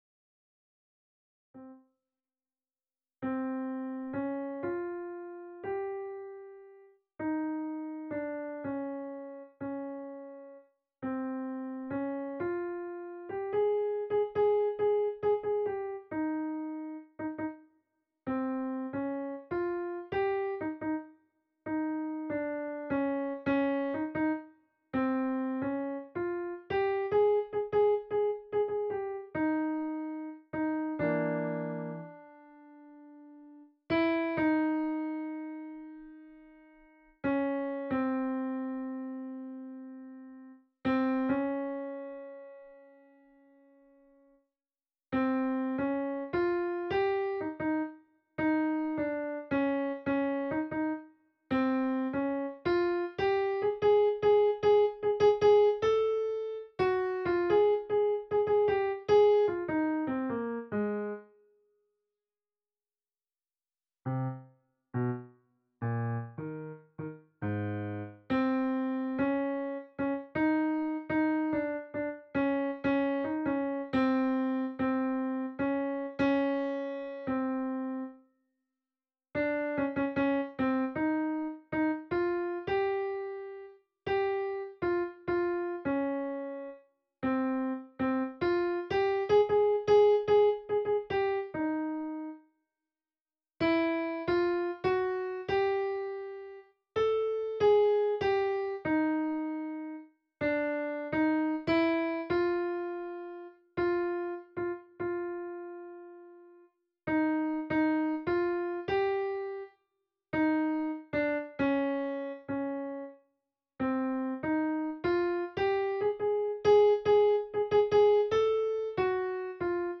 Øvefil for Alt (wav)